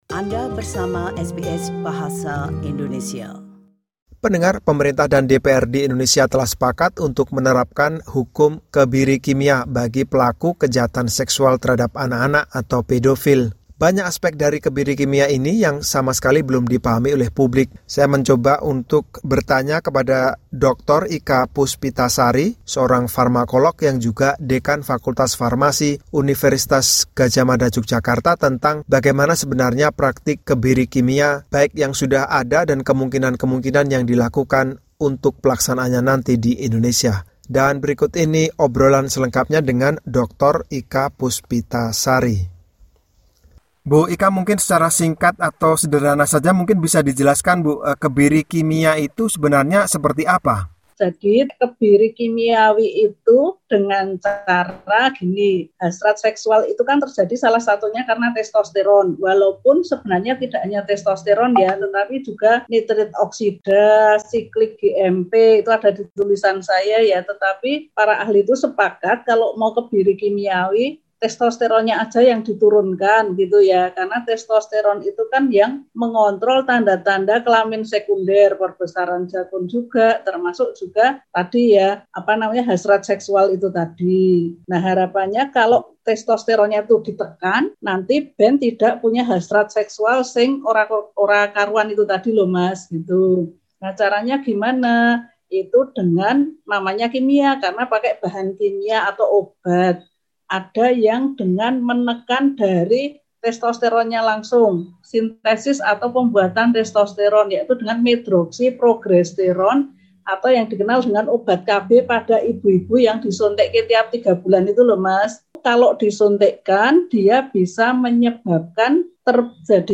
perbincangan